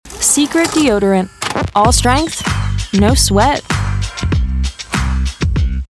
Sprecherin, Synchronsprecherin, Sängerin